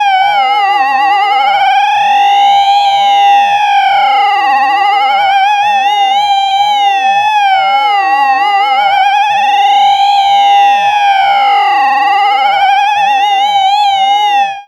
Vibrations.wav